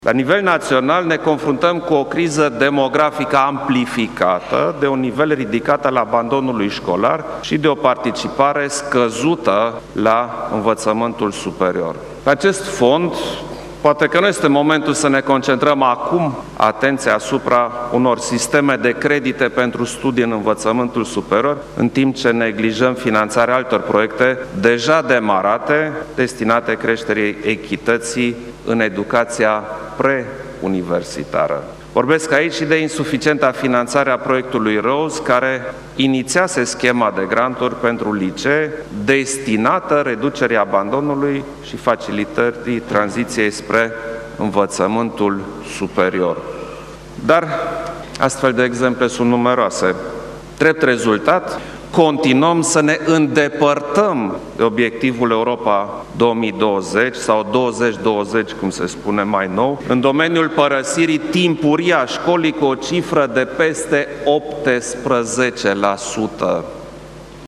Nu e suficient să asigurăm accesul la educaţie. Trebuie să garantăm accesul la educaţie de calitate. La nivel naţional ne confruntăm cu o criză demografică amplificată., abandon şcolar şi o participare scăzută la învăţământul superior. Poate nu e cazul acum să ne concentrăm creditele pentru învăţământul superior şi să neglijăm alte proiecte deja demarate”, a declarat Klaus Iohannis, la evenimentul de lansare World Development Report: „Learning to Realize Education’s Promise”.